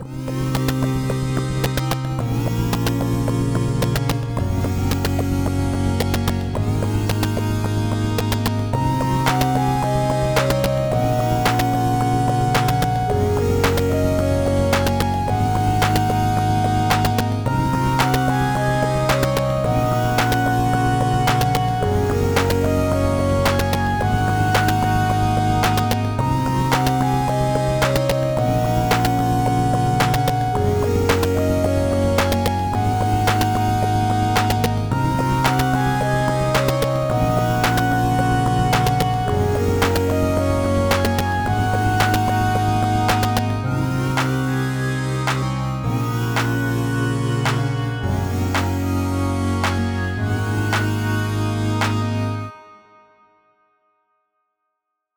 Cool sci-fi track to vibe to. Energetic but not high-octane.